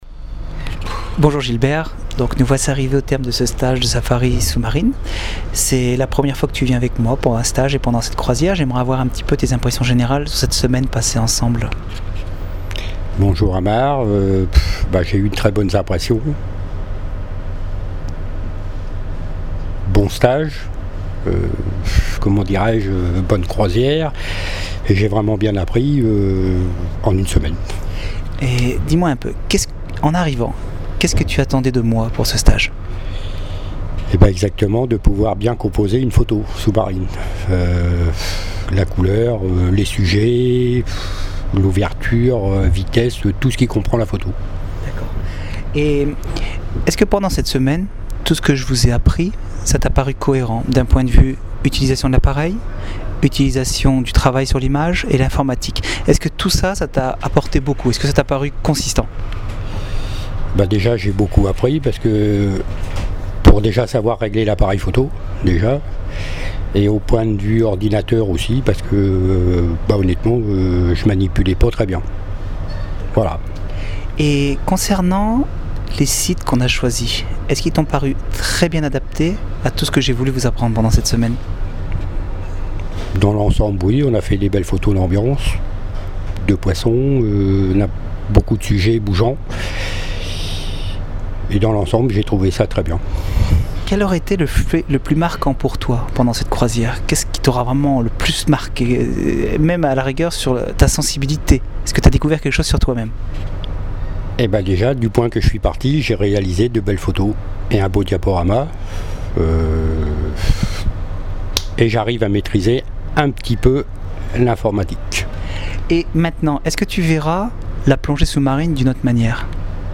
Le commentaire écrit et oral des stagiaires